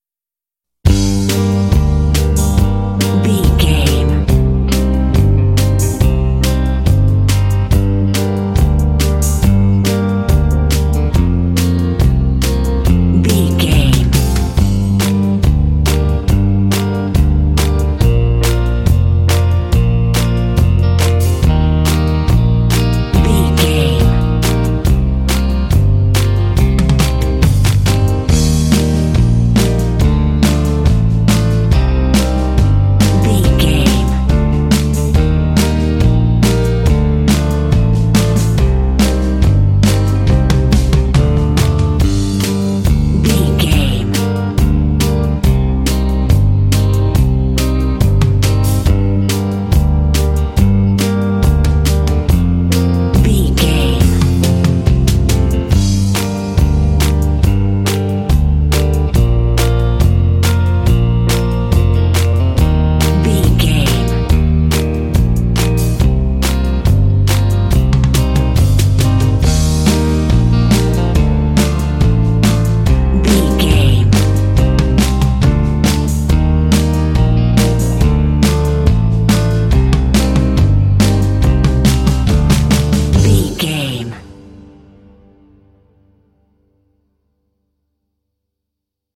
Aeolian/Minor
smooth
calm
groovy
drums
electric guitar
bass guitar
piano
contemporary underscore
country